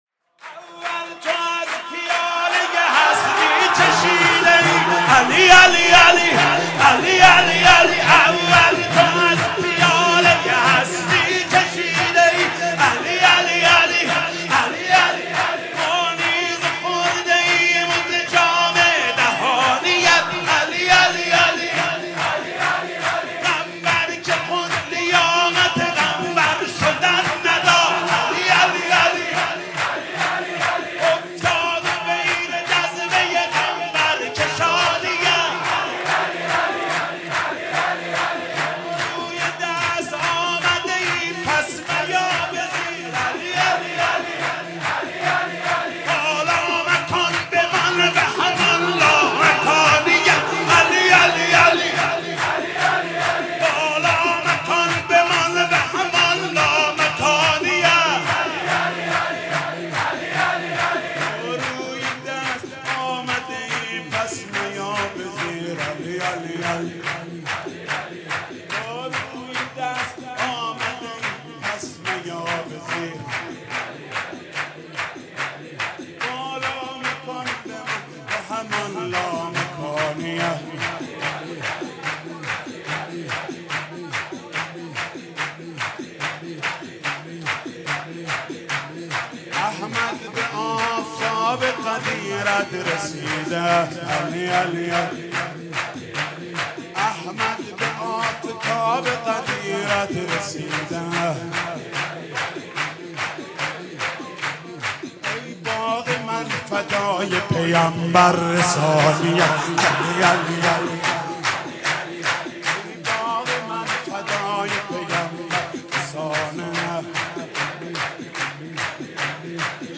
سرود: اول تو از پیاله هستی چشیده ای